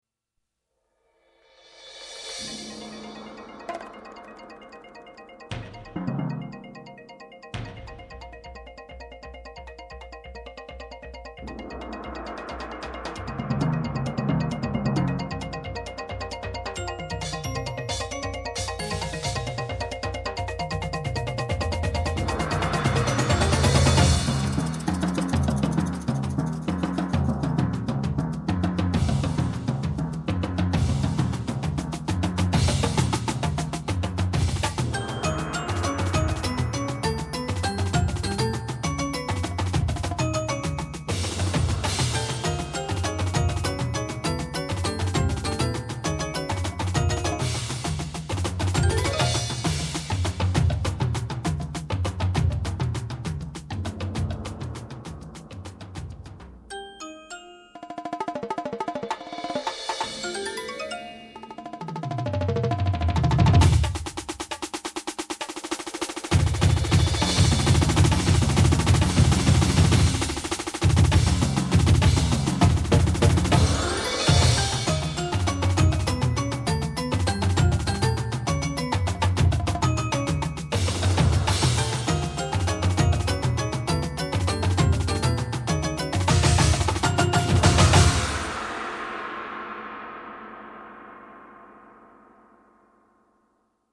Advanced Marching Feature